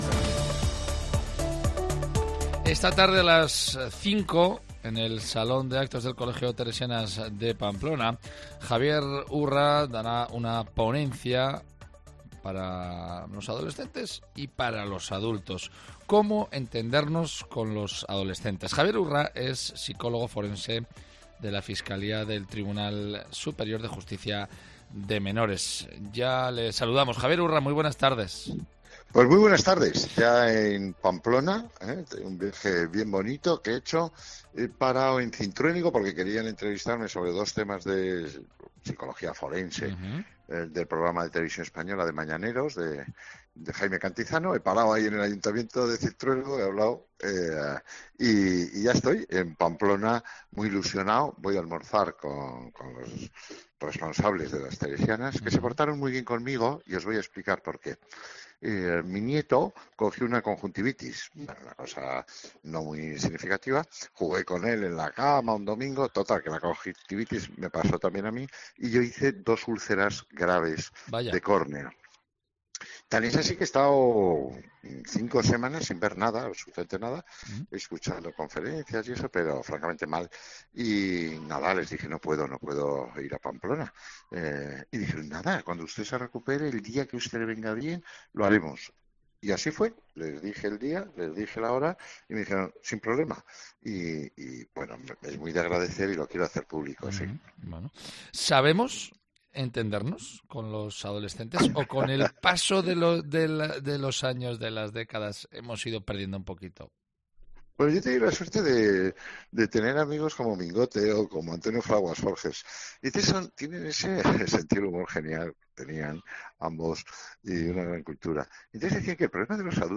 Javier Urra ha pasado por los micrófonos de Cope Navarra para tratar el tema precisamente.